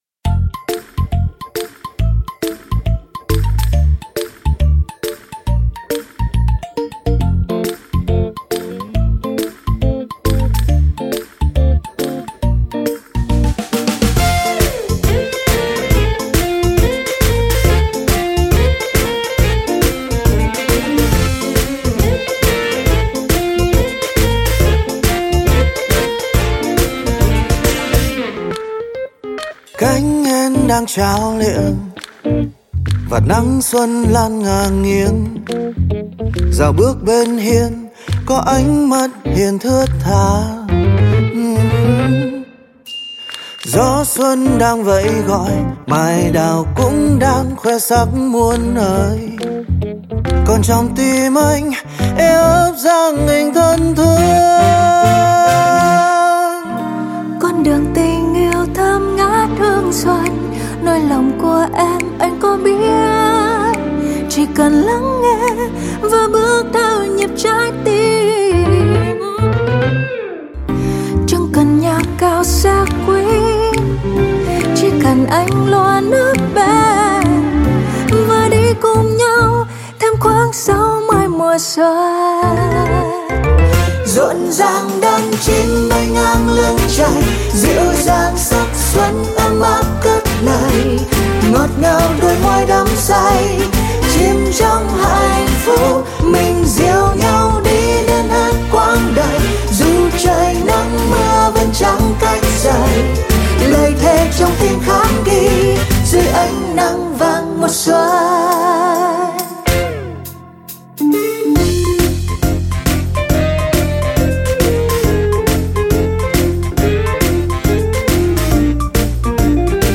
Sách nói | 60 mùa xuân